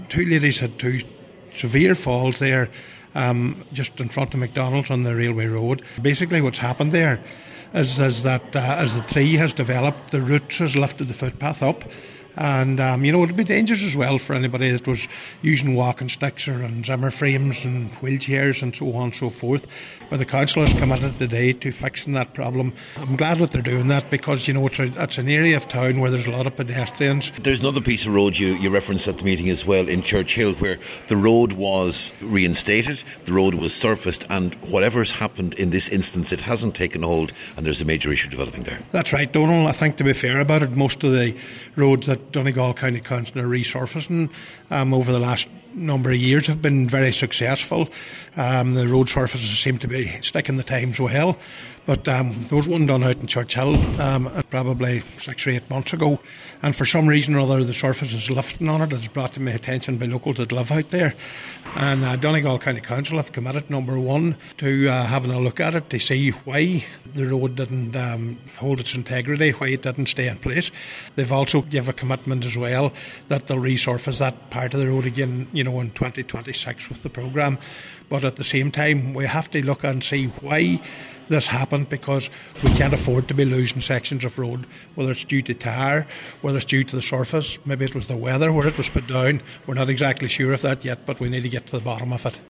Cllr McBride says this is a particularly serious problem because that stretch is very popular with runners and walkers: